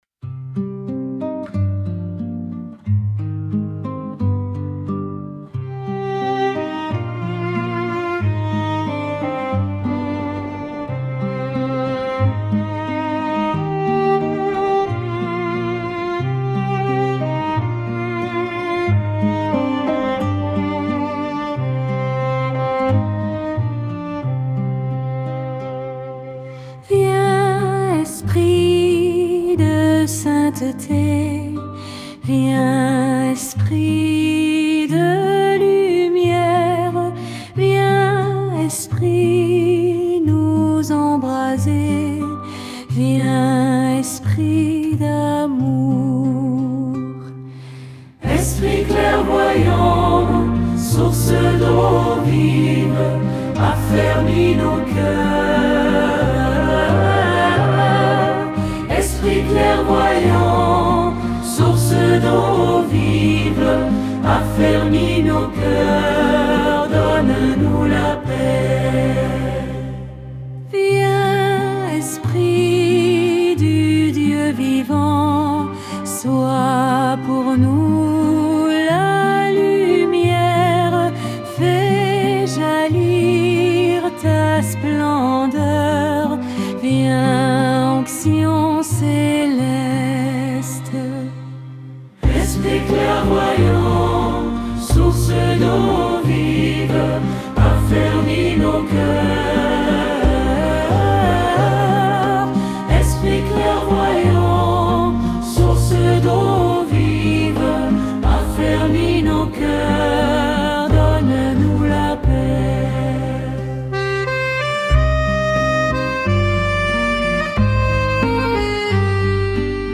Chant de pentecôte, invocation du sainte Esprit dans la prière